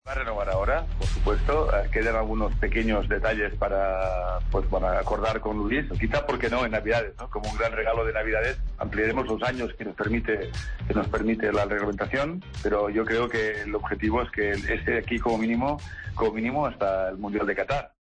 El presidente del Barcelona afirmó en una radio uruguaya que la renovación de Luis Suárez está cada vez más cerca.